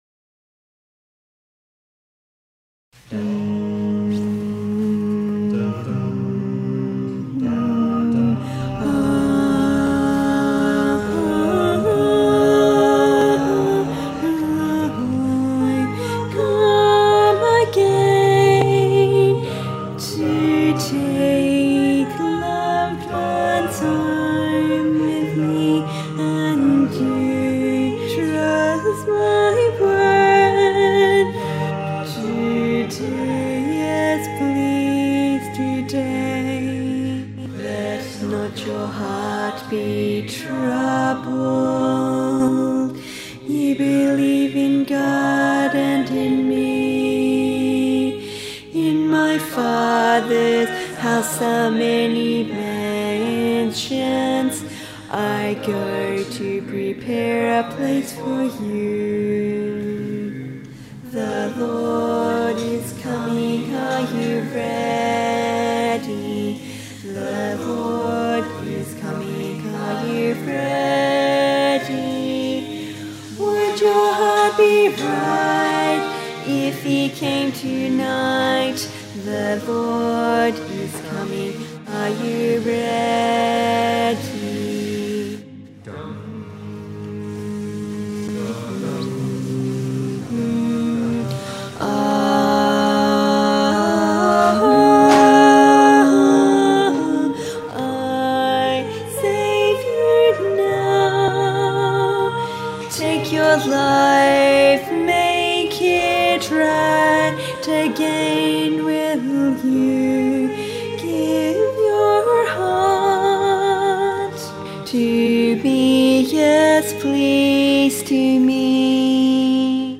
Music Camp 2017 Practice Recordings
Main Mix Soprano